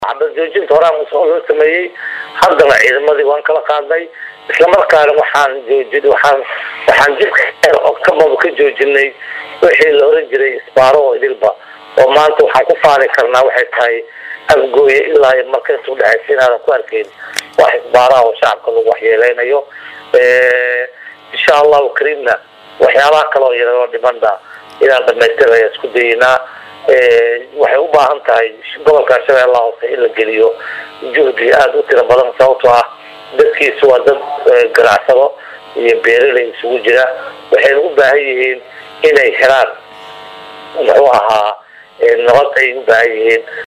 Halkan Ka Dhageyso Codka Wasiirka Beeraha iyo Waraabka Ee KGS.